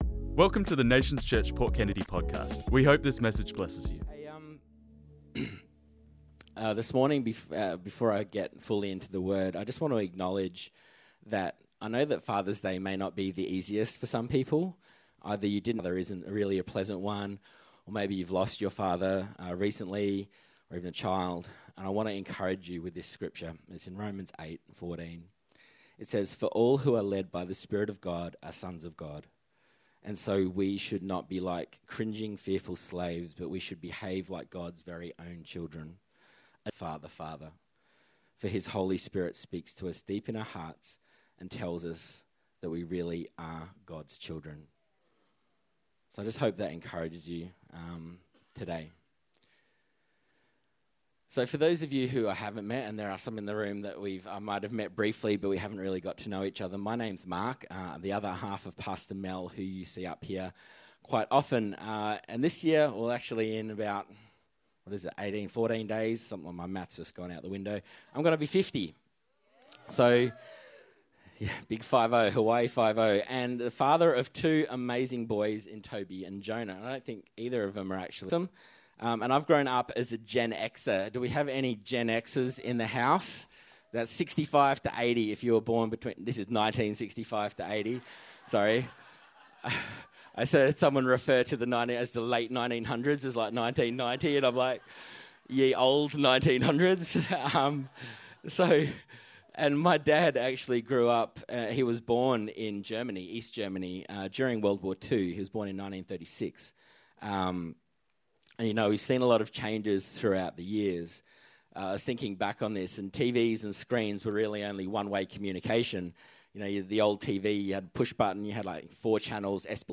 This message was preached on Sunday the 7th September 2025